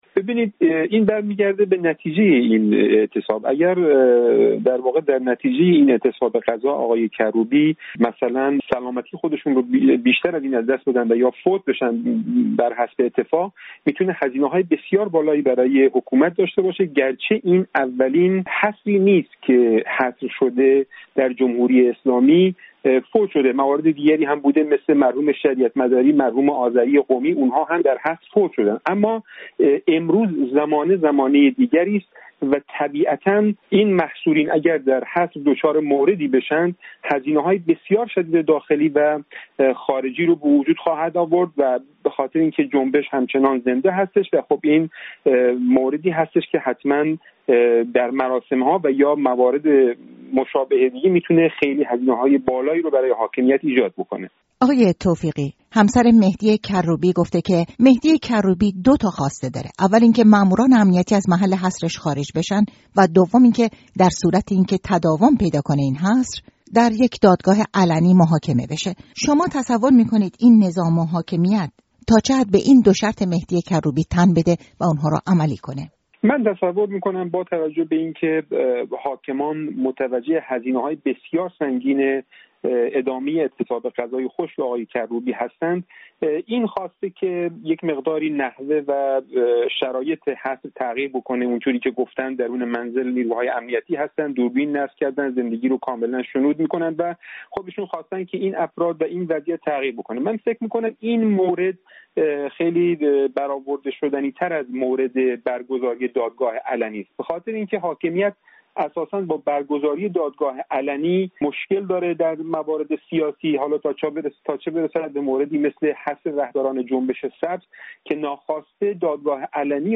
گفت‌وگوی
تحلیلگر سیاسی، درباره اعتصاب غذای کروبی